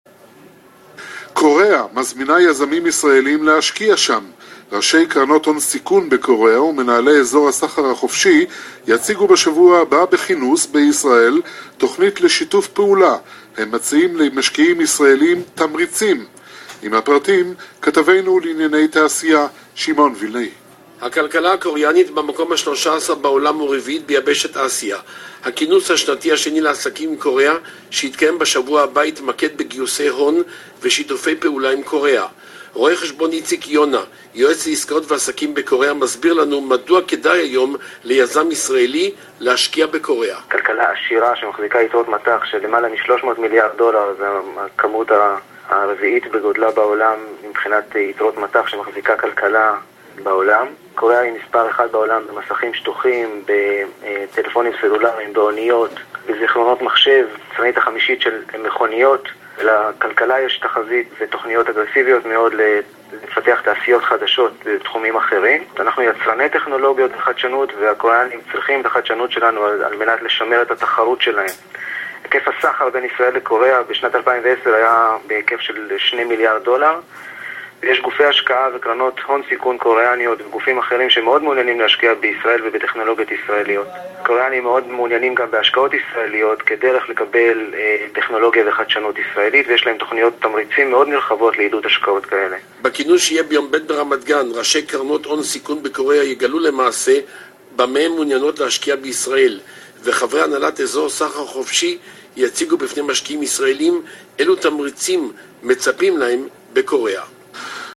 Interview from Reshet Bet – “Money Talks”